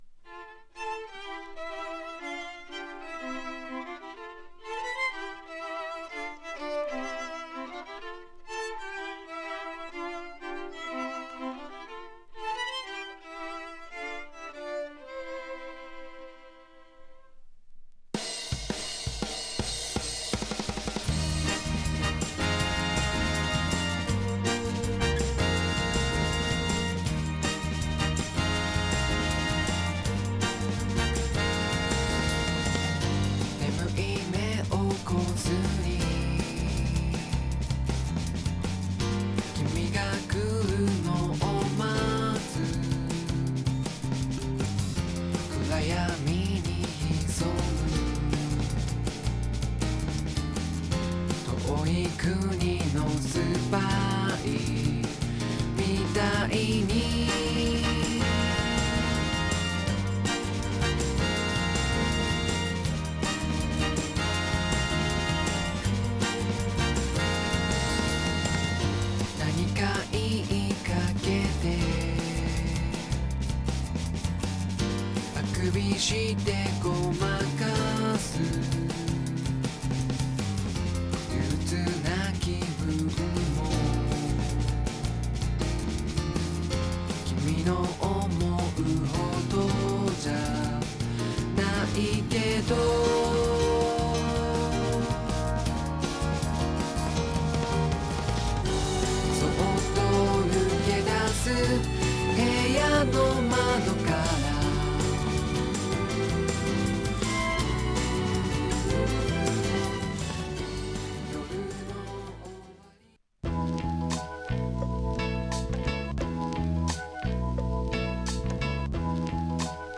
90年代国産ソフト・ロックの最高峰の１つ!!